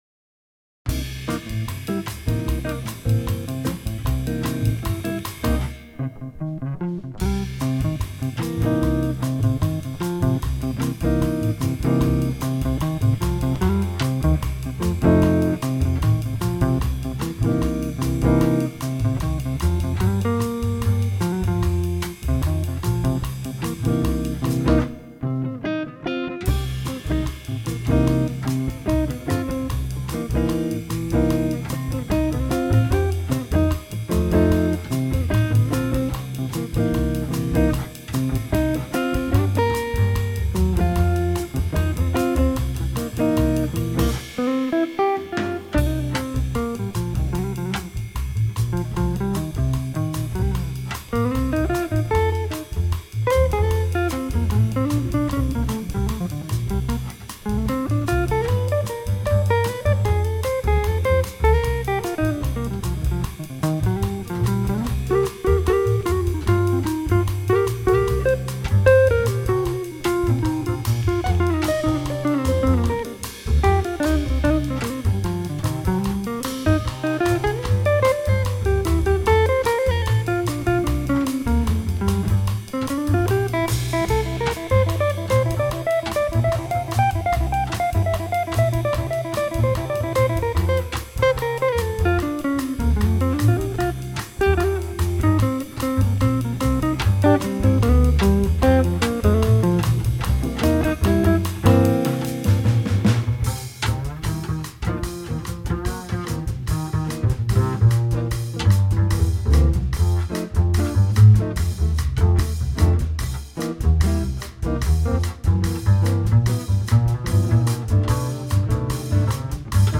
Gitarre
Kontrabass
Schlagzeug